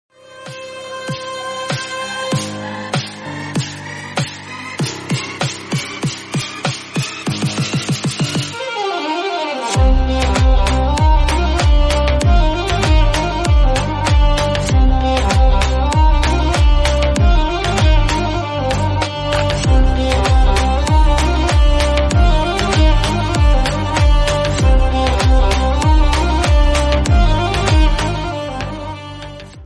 BGM Instrumental Ringtone